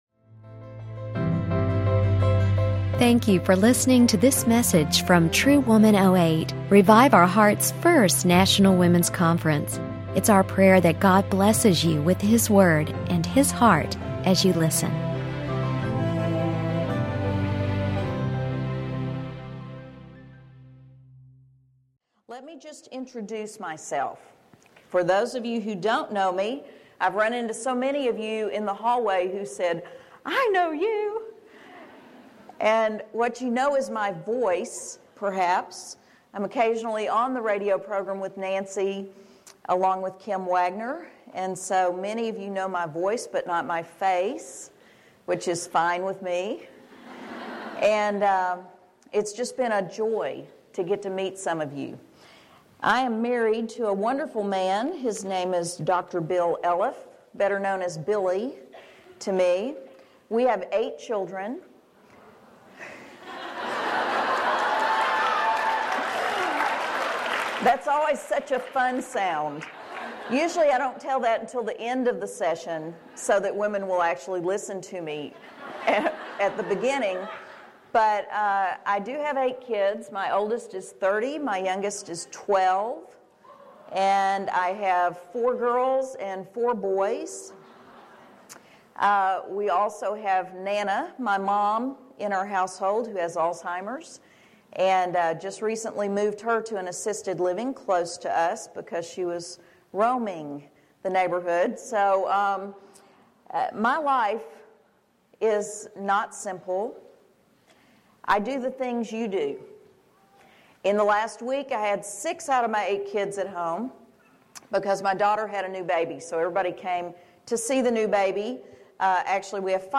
Turning the Tide | True Woman '08 | Events | Revive Our Hearts